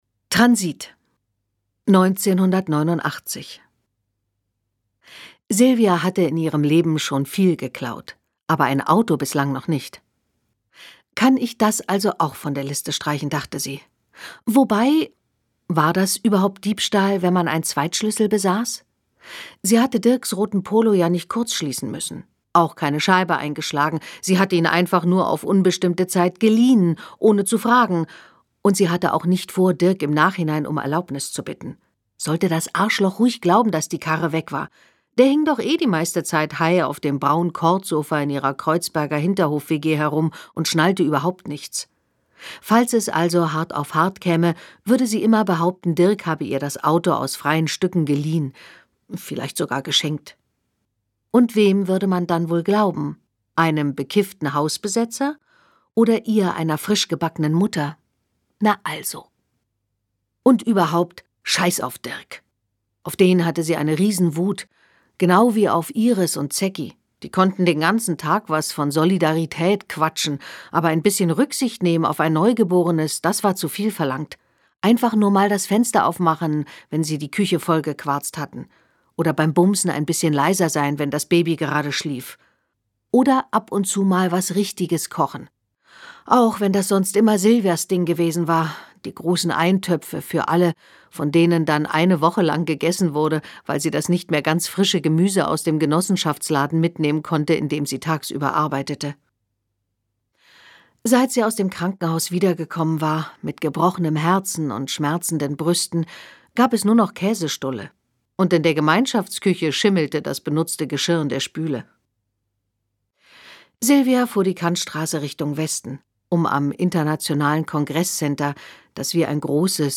Mit warmer Stimme und einfühlsam gelesen